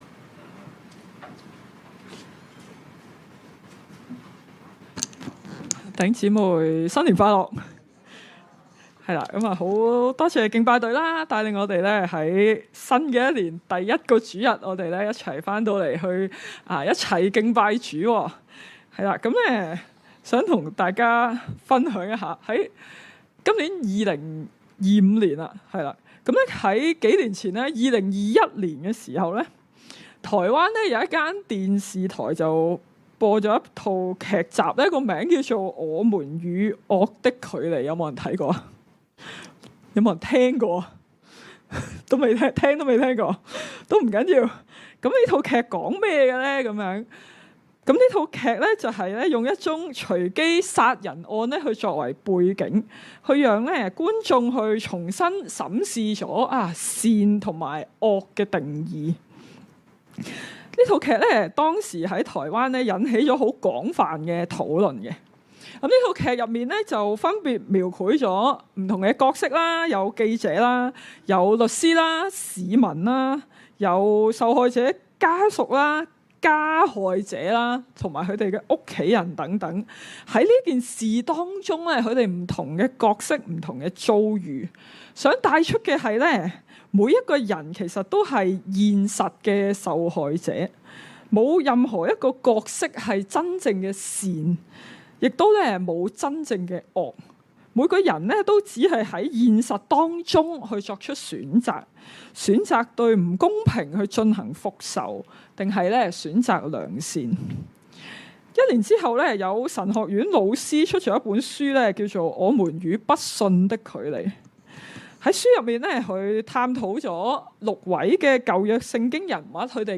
證道重溫
恩福馬鞍山堂崇拜-早堂